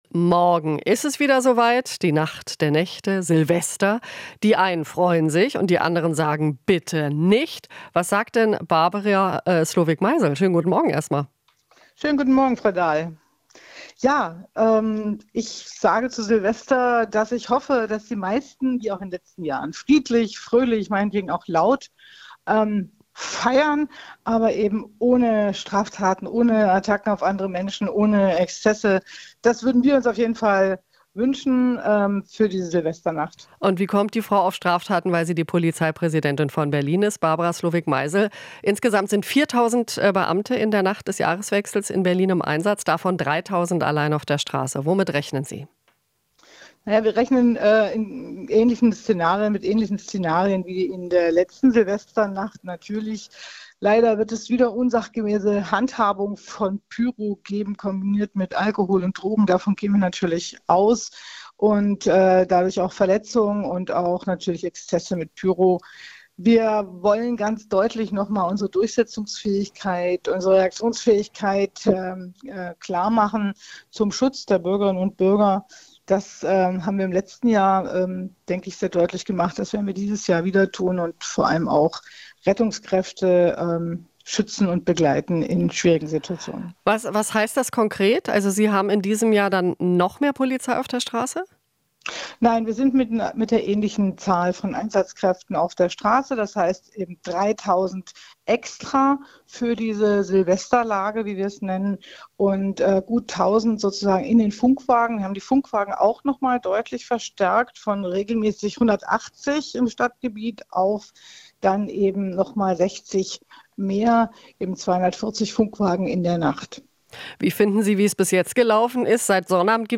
Interview - Polizeipräsidentin Slowik Meisel hofft auf friedliche Silvesternacht in Berlin